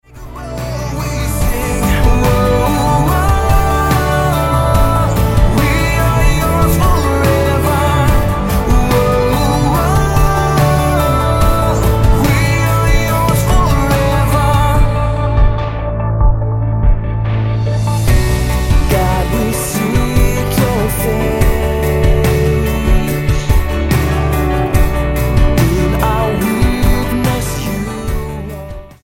STYLE: Pop
which sounds churchy in the extreme